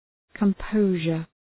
Προφορά
{kəm’pəʋʒər}